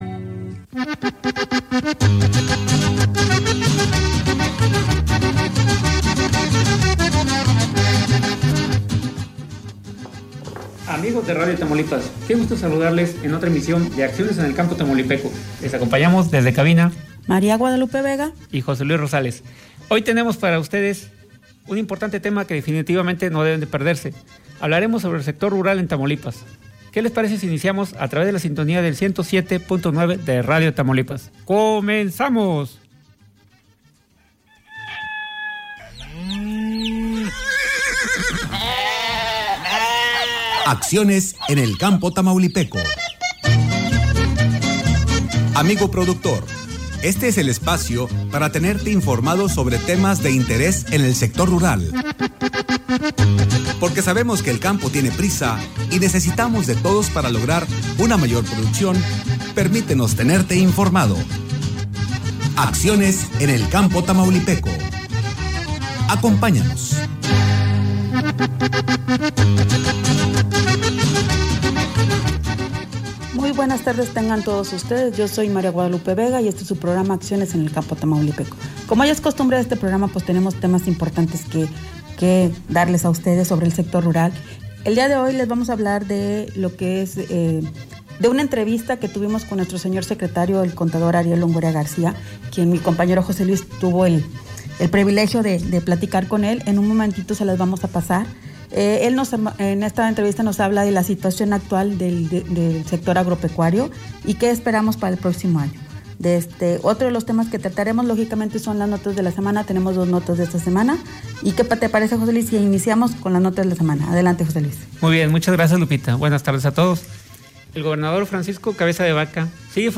En esta última transmisión del año se tuvo una entrevista con el Secretario de Desarrollo Rural el C.P. Ariel Longoria García, quien nos platicó sobre El Sector Rural en Tamaulipas de como nos fue en este año, un panorama general del sector tanto agrícola como pecuario y forestal.